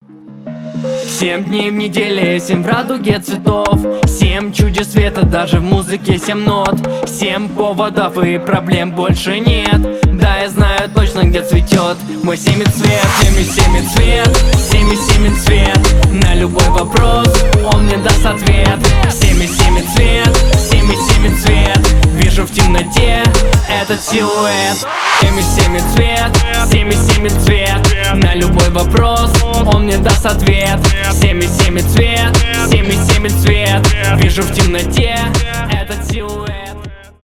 РИНГТОН
2025 » Новинки » Русские » Рэп Скачать припев